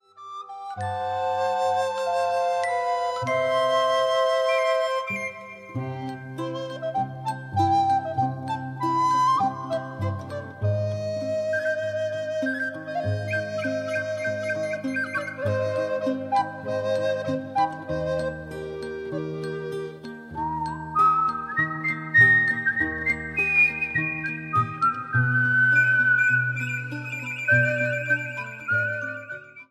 古楽器たちが踊り出す。
心地よい古楽器のアンサンブルをお楽しみ下さい。